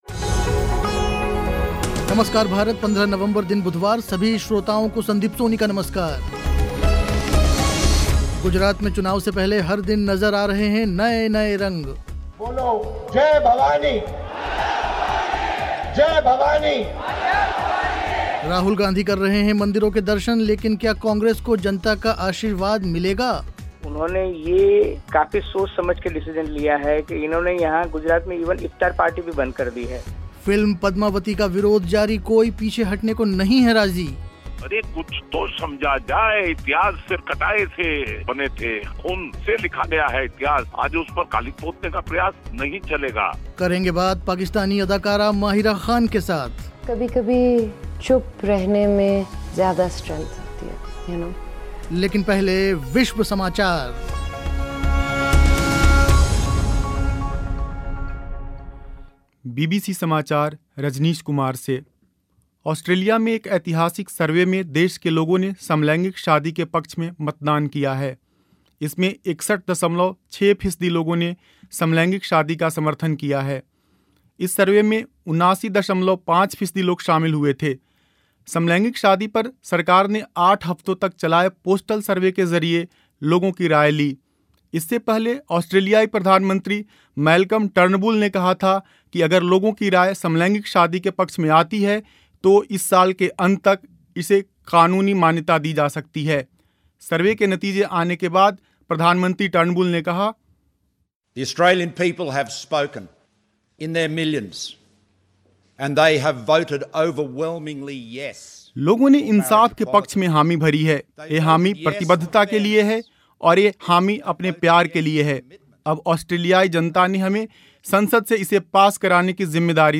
करेंगे बात पाकिस्तानी अदाकारा माहिरा ख़ान के साथ.